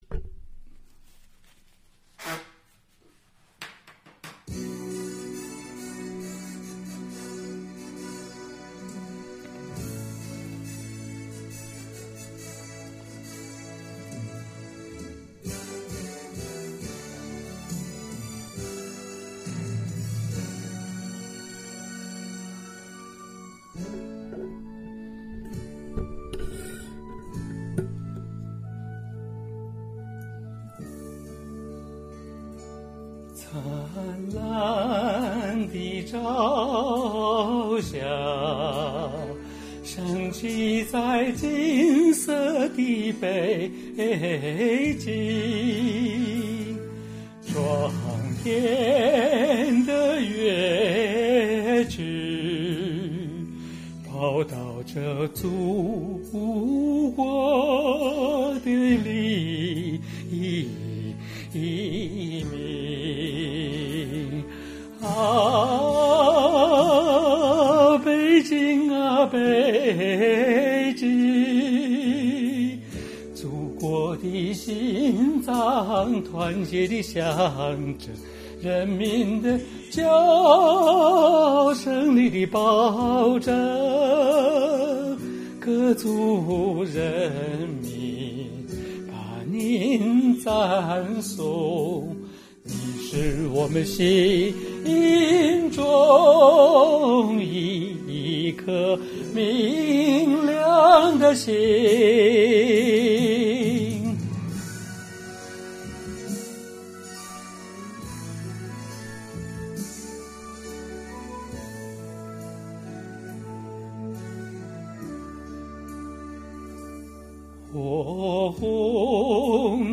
歌曲是用数字录音机录的， 所以音质就无法谈起了。
外录好后发现有些地方音不准，但我现在没机会再录。